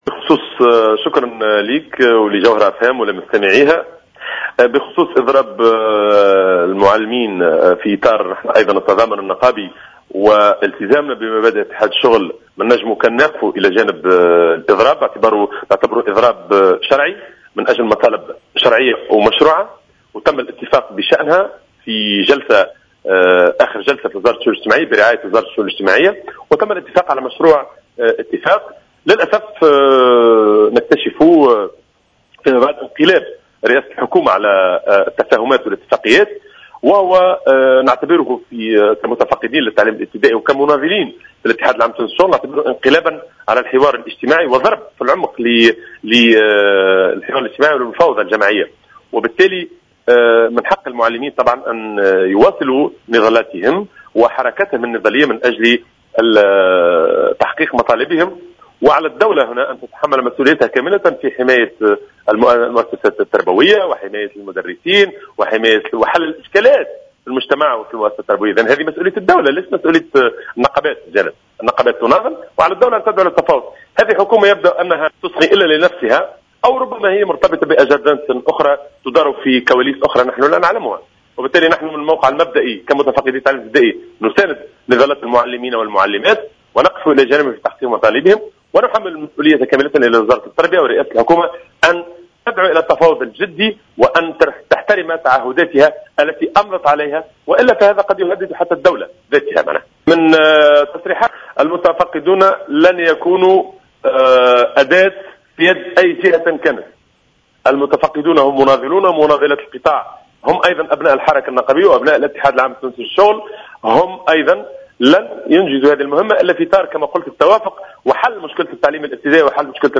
في تصريح لجوهرة "اف ام" صباح اليوم